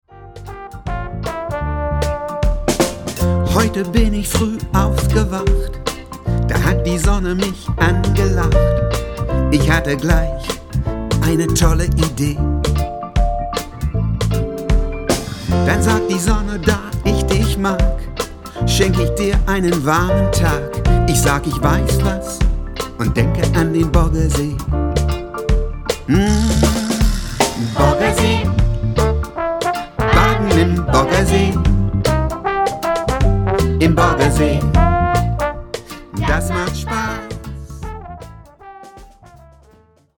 Gute-Laune-Songs für Klein & Groß